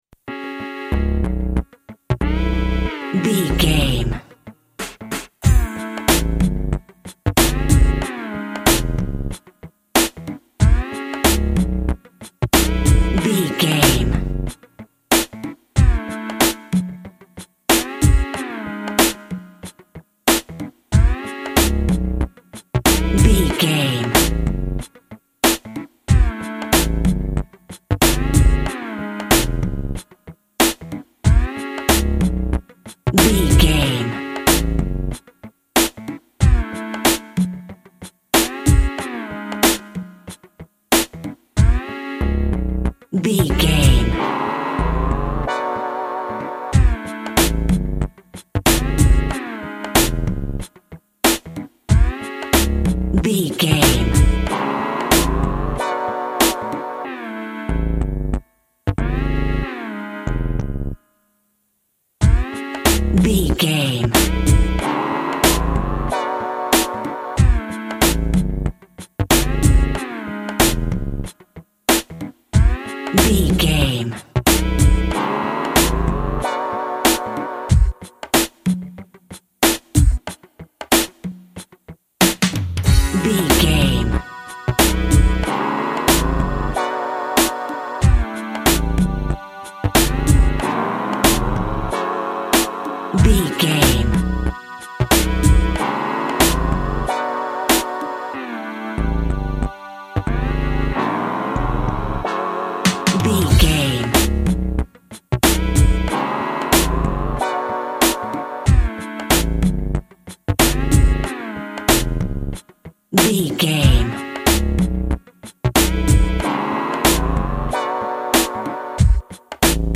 Hip Hop Winding Up.
Aeolian/Minor
synth lead
synth bass
hip hop synths